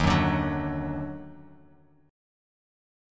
D#mM7 chord